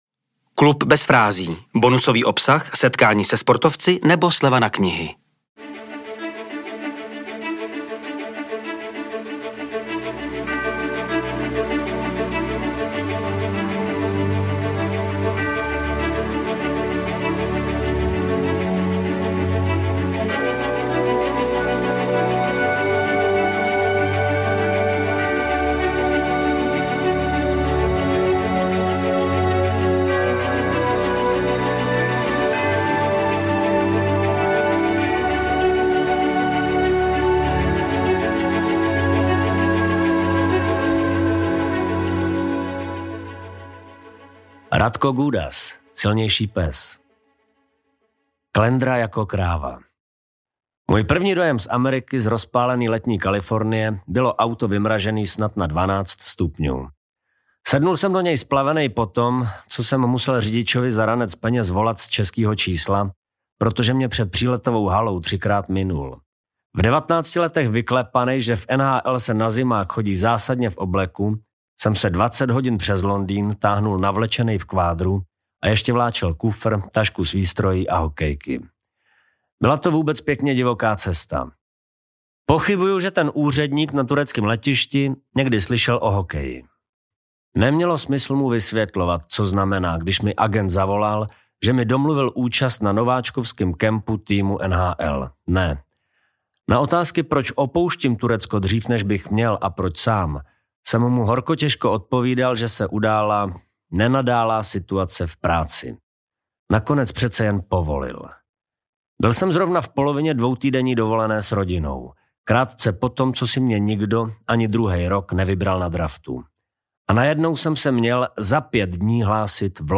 Číst vám ho bude Martin Hofmann.